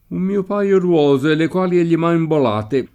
imbolare v.; imbolo [imb1lo] — variante ant. di involare, usata soprattutto nei sign. materiali («portar via; rubare»): un mio paio d’uose le quali egli m’ha imbolate [